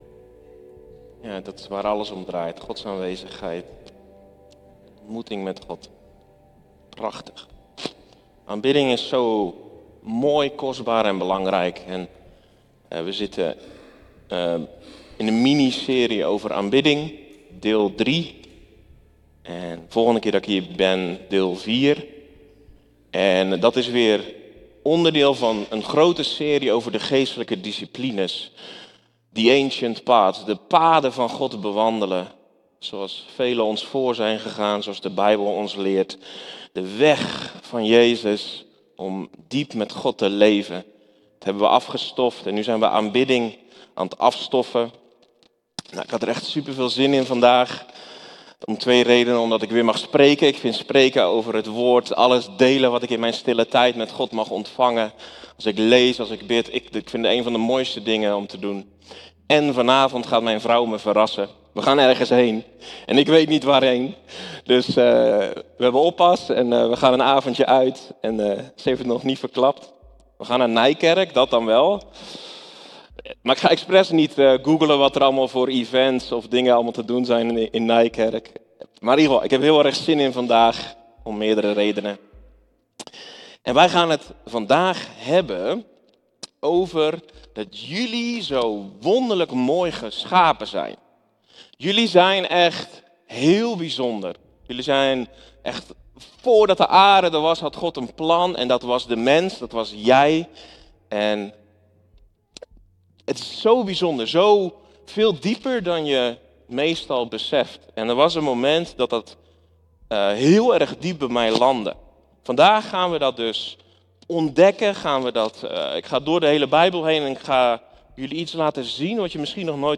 Een belangrijk moment in de preek was toen hij de inwijding van de tempel van Salomo koppelde aan Pinksteren.